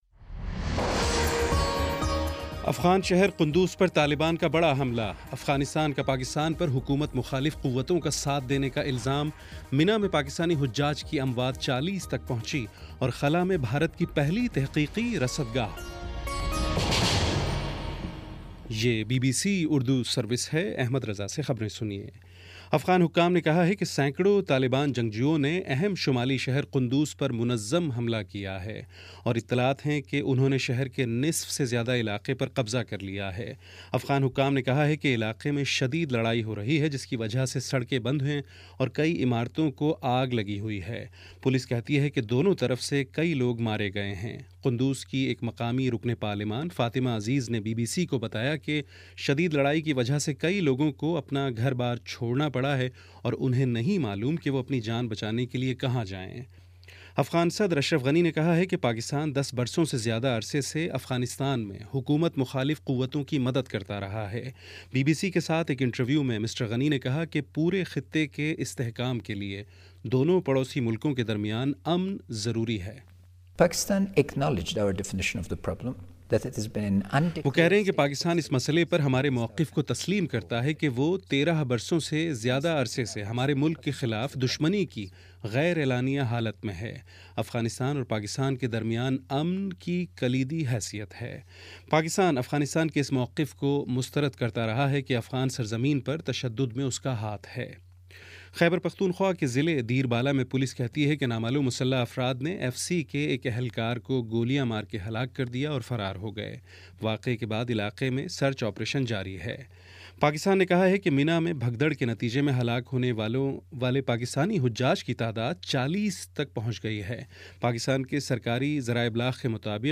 ستمبر28 : شام چھ بجے کا نیوز بُلیٹن